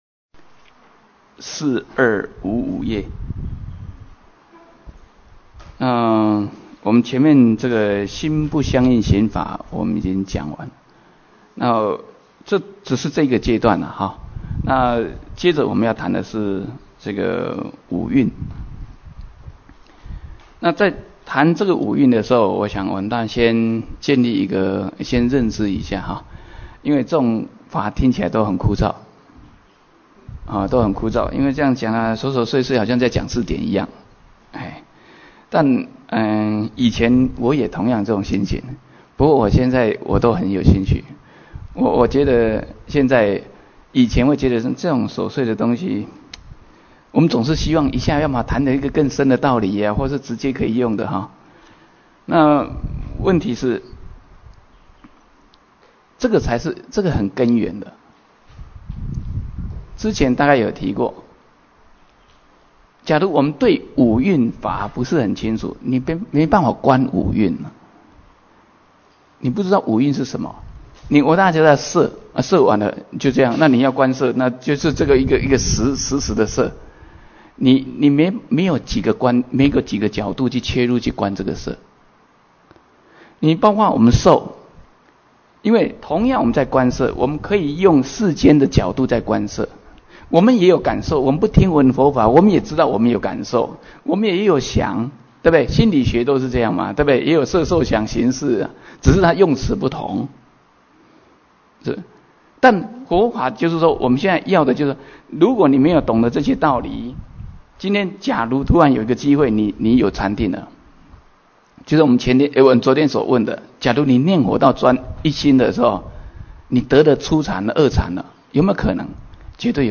瑜伽师地论摄抉择分048(音軌有損.只13分).mp3